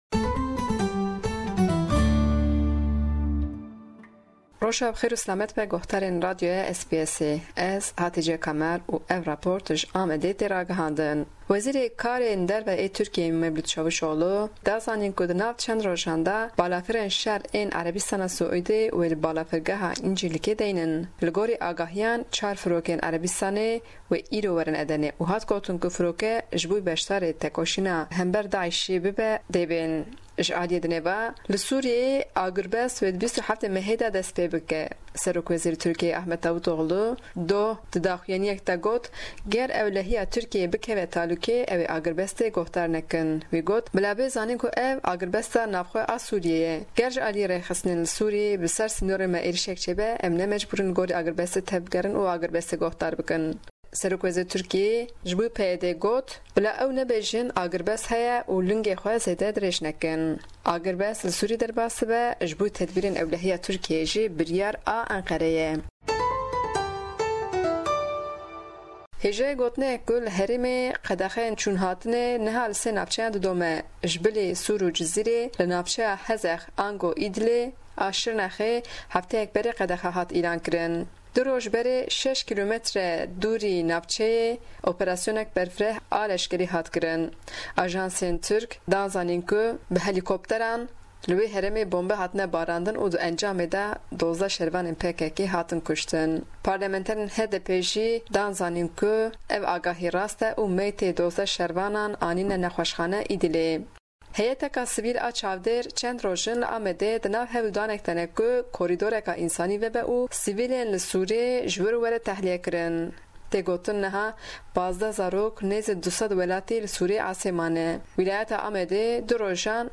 hevpeyvina